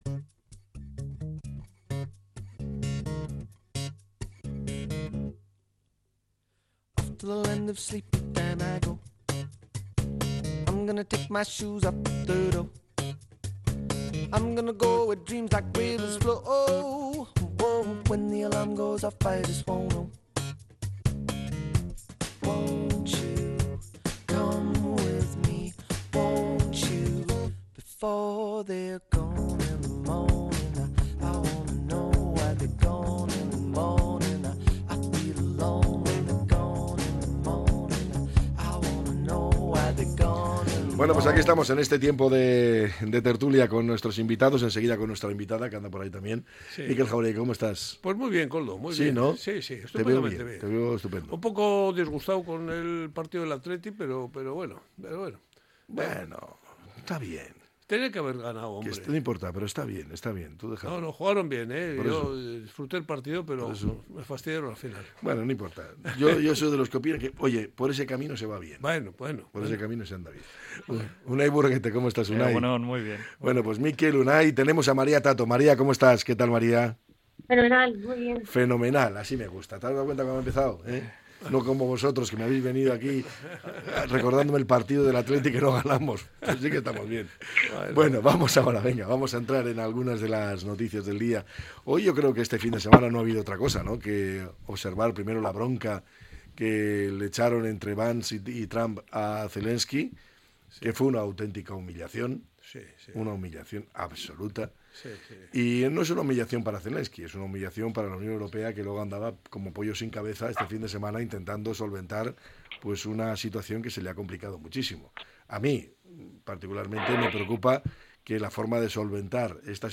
La tertulia 03-03-25.